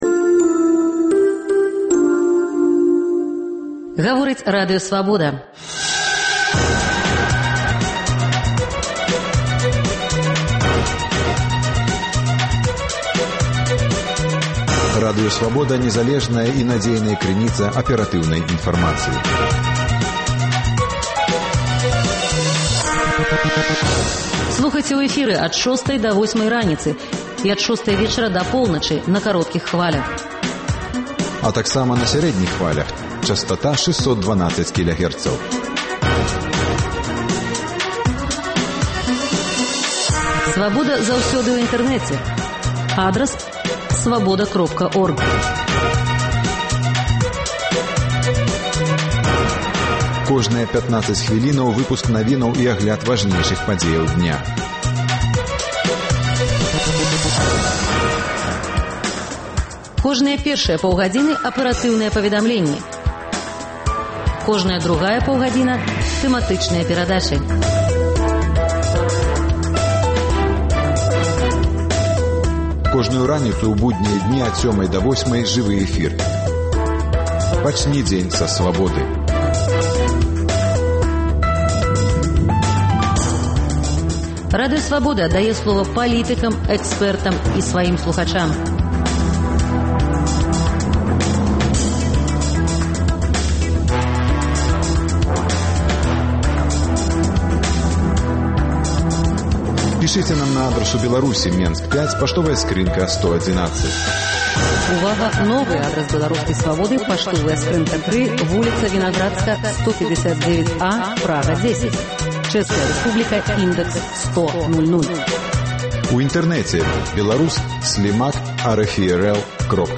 Навіны Беларусі й сьвету, надвор'е, агляд друку, гутарка з госьцем, ранішнія рэпартажы, бліц-аналіз, музычная старонка